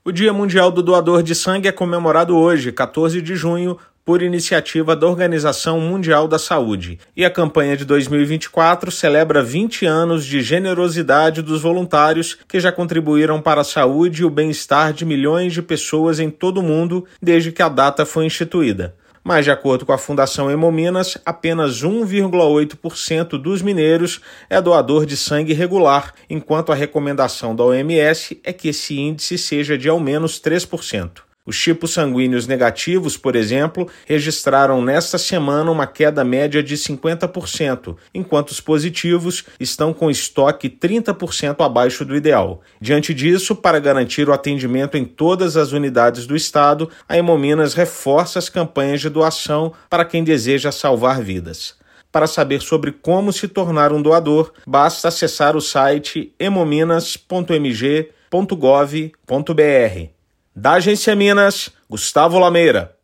Data é celebrada nesta sexta-feira (14/6) e fundação ressalta a importância de manter uma rede de doadores, fundamental para salvar vidas. Ouça matéria de rádio.